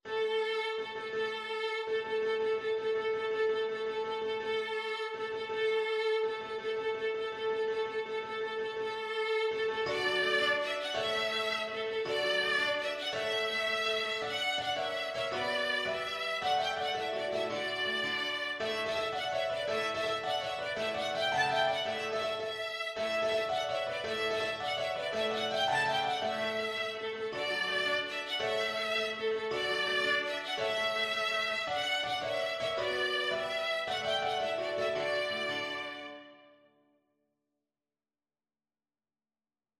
D major (Sounding Pitch) (View more D major Music for Violin )
6/8 (View more 6/8 Music)
With energy .=c.110
Violin  (View more Intermediate Violin Music)
Classical (View more Classical Violin Music)